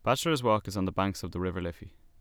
Dublin accent